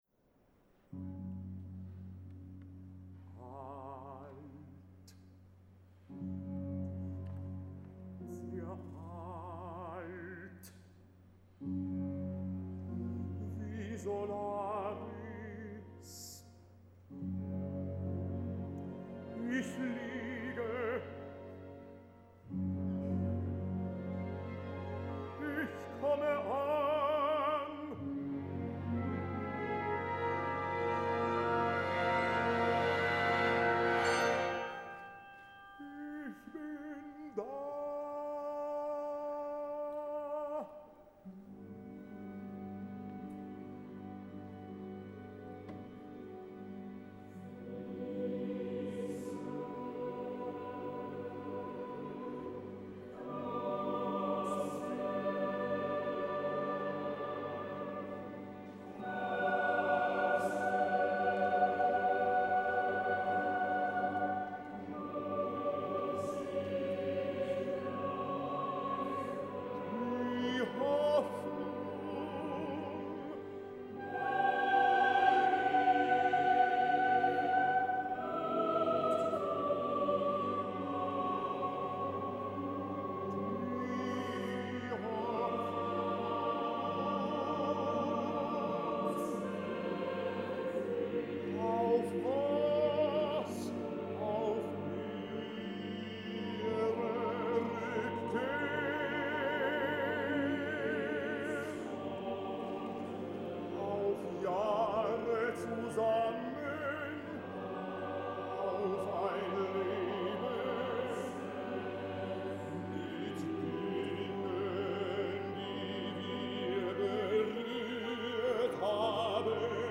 Juli 2012 im Bregenzer Festspielhaus seine Welturaufführung.